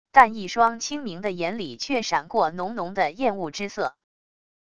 但一双清明的眼里却闪过浓浓的厌恶之色wav音频生成系统WAV Audio Player